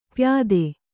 ♪ pyāde